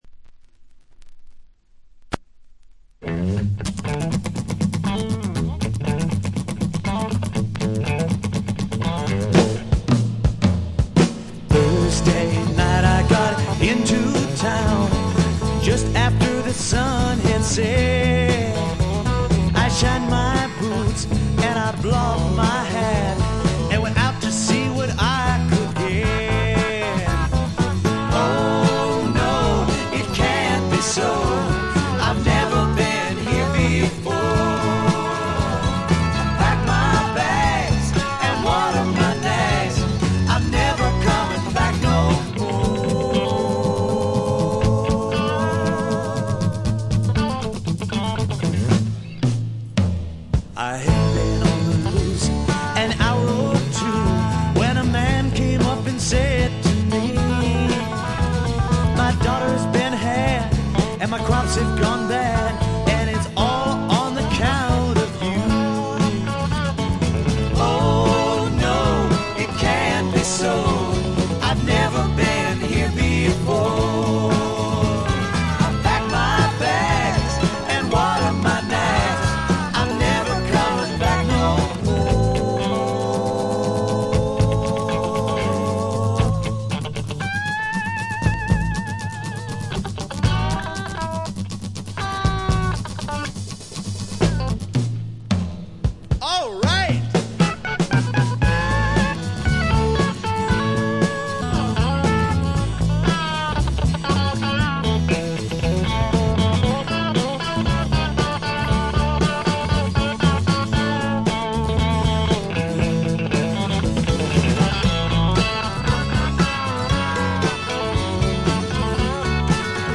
ホーム > レコード：英国 SSW / フォークロック
英国シンガー・ソングライター基本中の基本。
試聴曲は現品からの取り込み音源です。